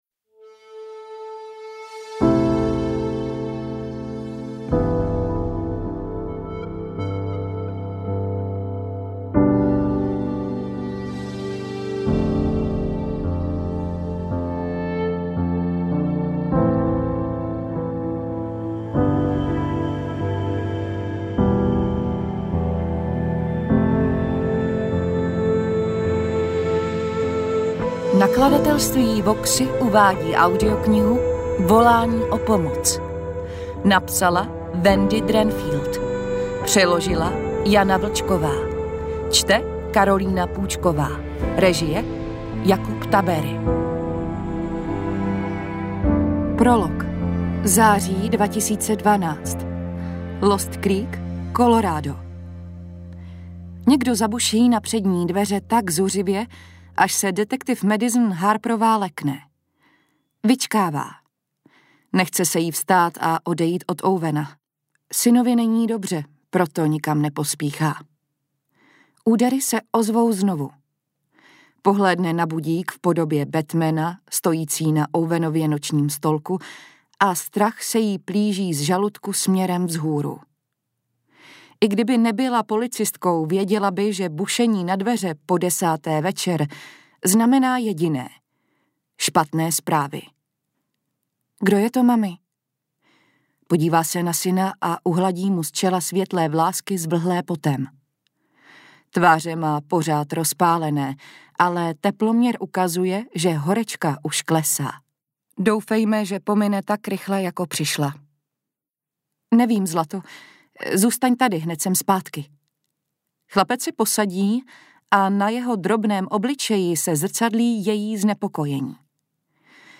AudioKniha ke stažení, 73 x mp3, délka 11 hod. 10 min., velikost 608,3 MB, česky